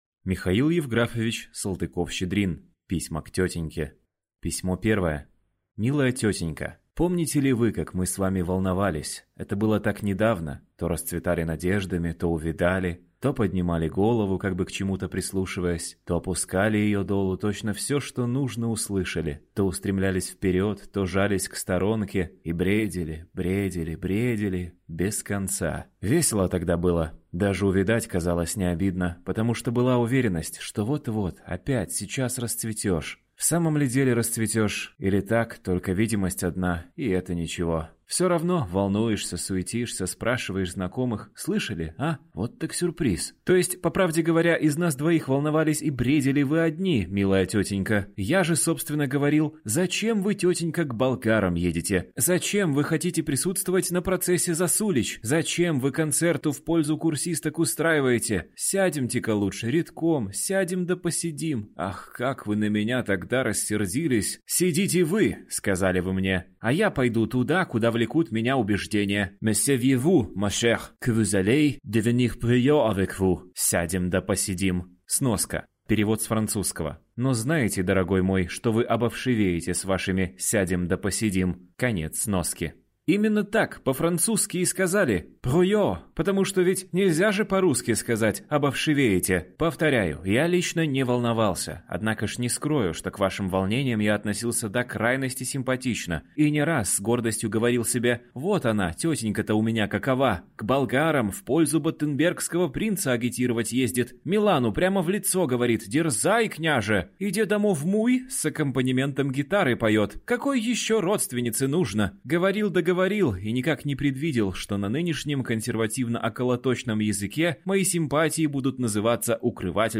Аудиокнига Письма к тетеньке | Библиотека аудиокниг